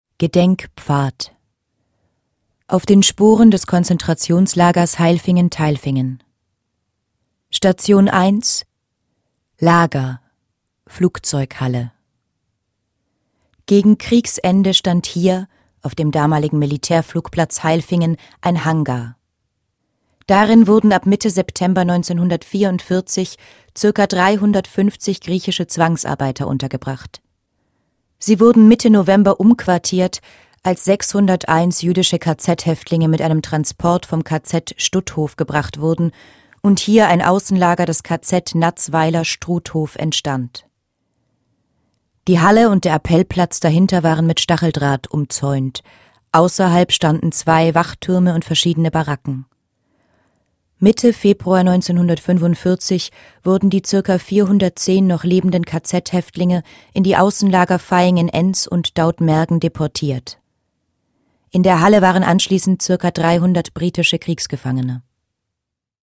Audioguide Basisinfo
mittlere Audioqualität